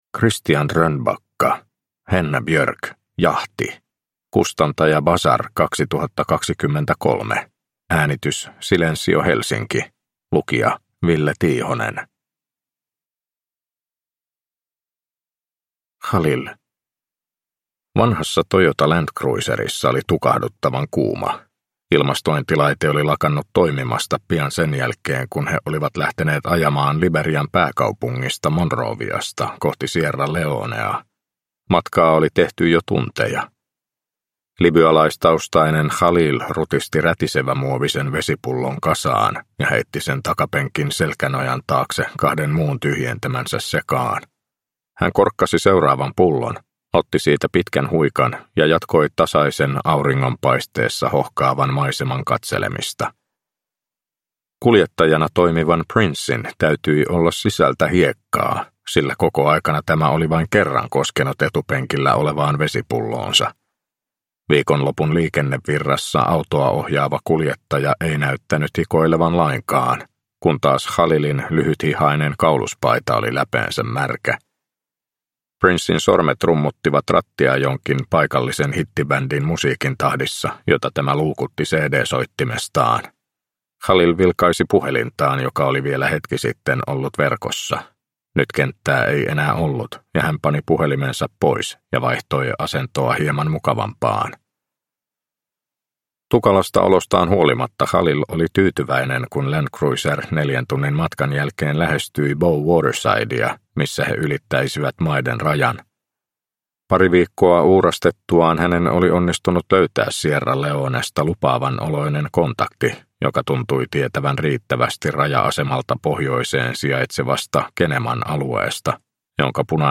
Henna Björk: Jahti – Ljudbok – Laddas ner